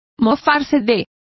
Complete with pronunciation of the translation of taunt.